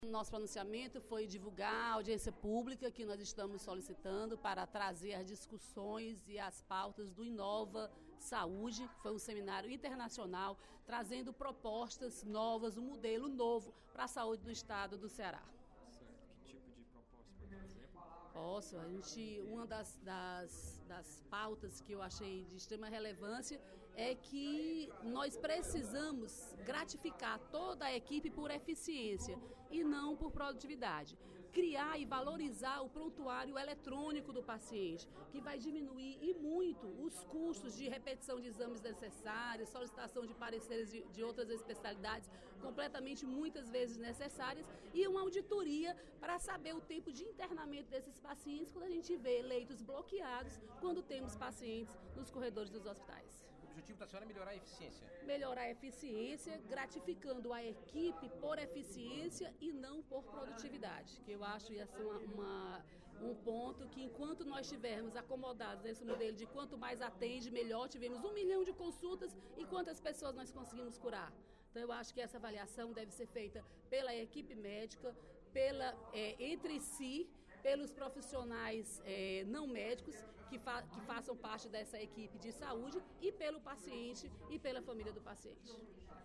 A deputada Dra. Silvana (PMDB) informou, durante o primeiro expediente da sessão plenária desta quarta-feira (12/08), que vai propor a realização de audiência pública para debater as pautas do Inova Saúde, seminário internacional de saúde realizado na última semana, na sede da Federação das Indústrias do Estado (Fiec).